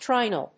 音節tri・nal 発音記号・読み方
/trάɪnl(米国英語)/